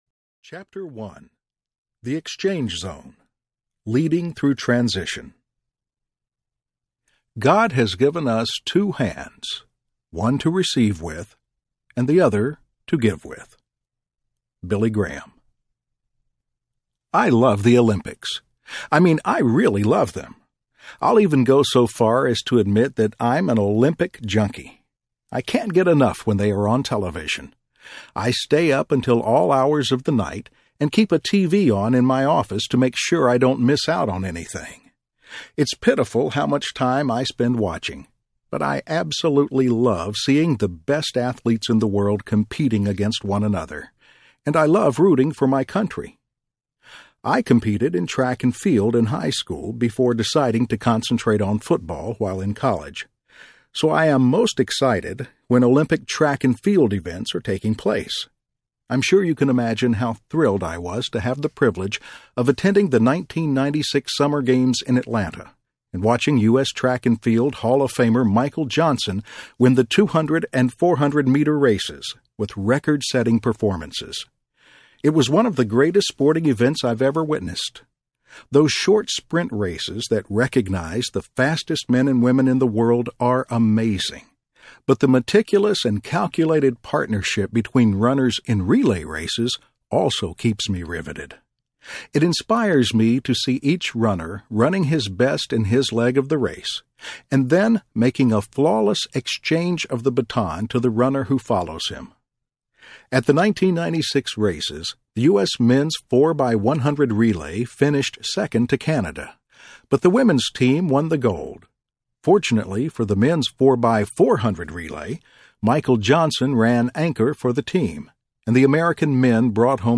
Passing the Leadership Baton Audiobook
Narrator
5.5 Hrs. – Unabridged